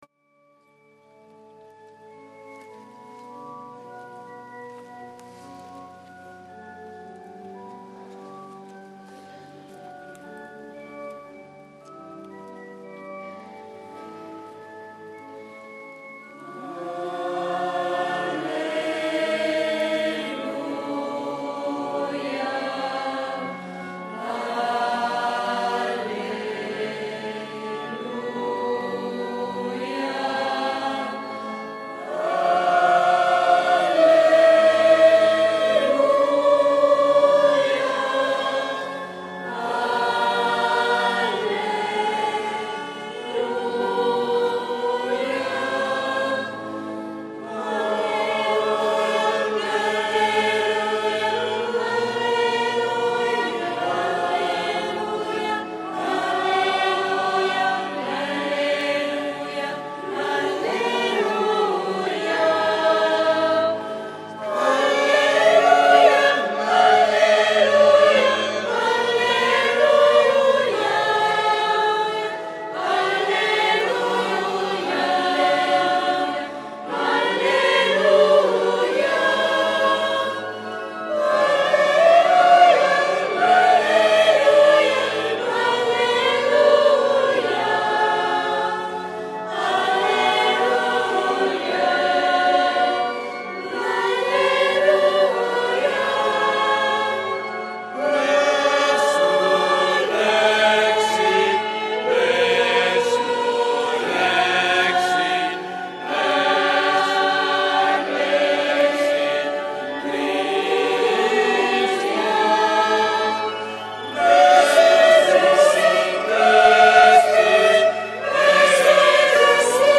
SABATO SANTO -Celebrazione della Resurrezione del Signore
canto: